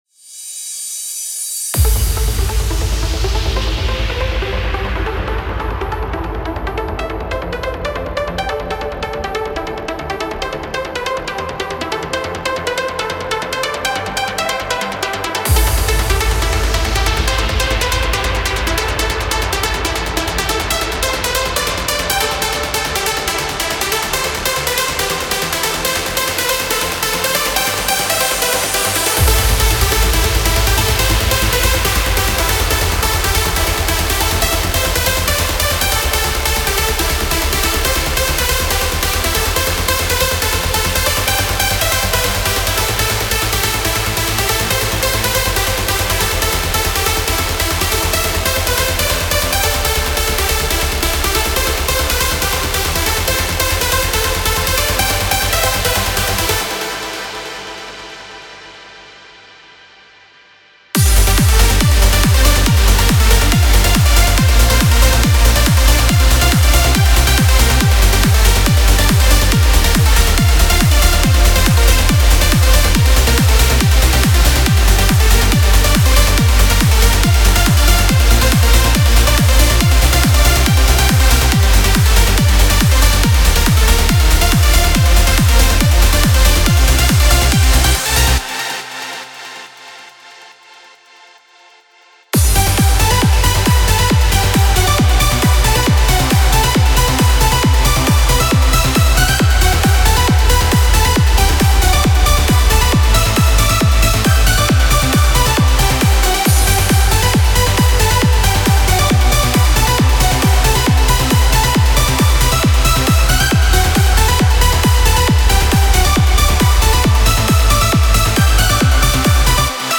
Genre: Trance Uplifting Trance